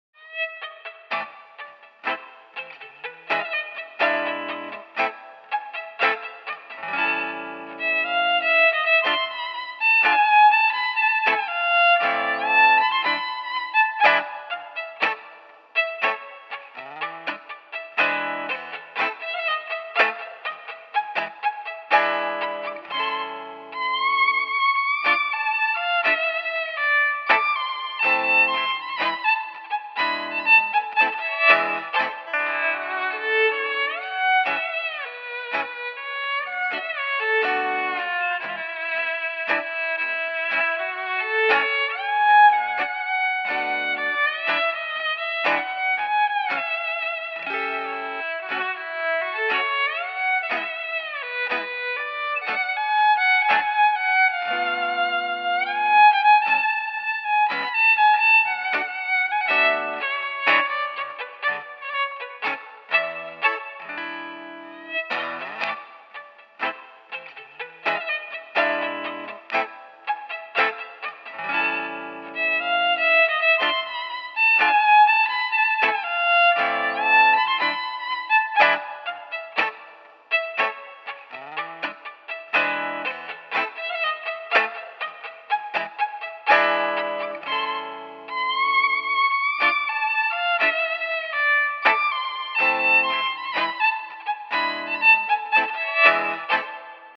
I'm a tango violinist who migrated from the US to Argentina in 2002. This is a tango I improvised & my friend put some guitar to it.
the rhythmic swing that belongs to Buenos Aires
Recorded in Buenos Aires, Argentina